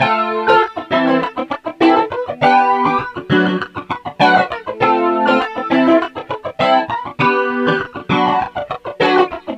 Sons et loops gratuits de guitares rythmiques 100bpm
Guitare rythmique 23